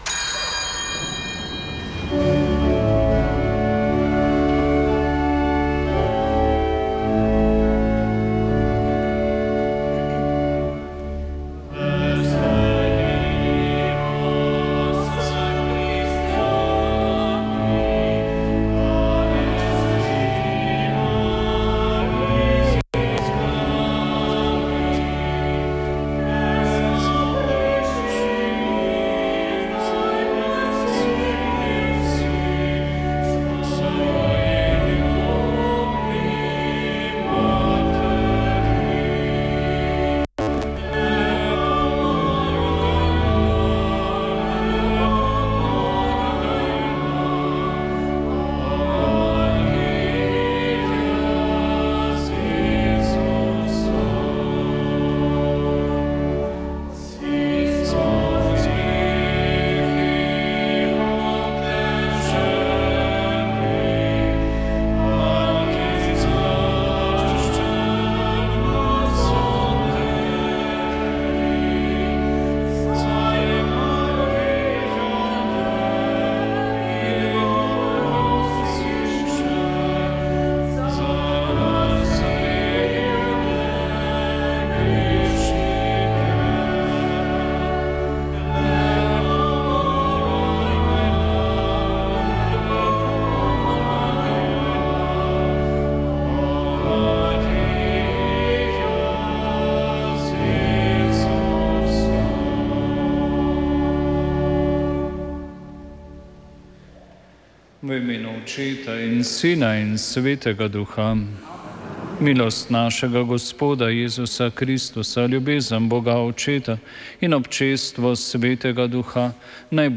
Sv. maša iz bazilike Marije Pomagaj na Brezjah 2. 5.